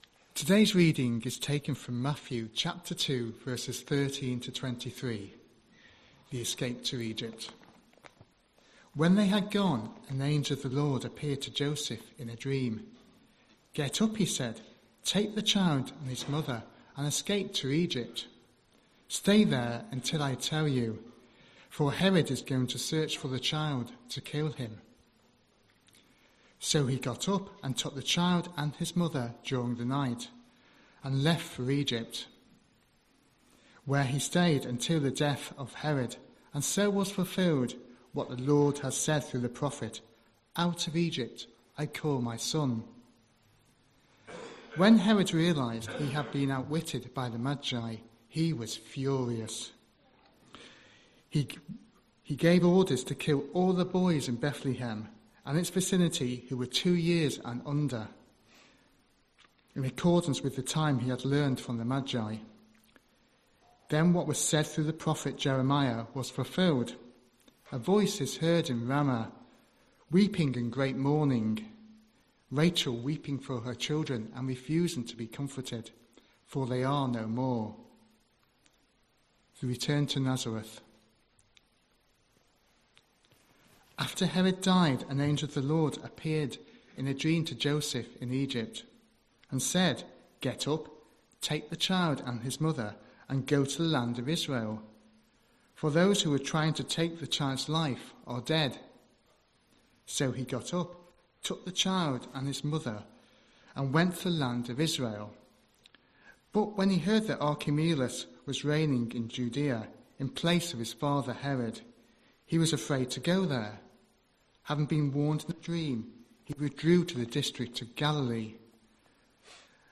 28th December 2025 Sunday Reading and Talk - St Luke's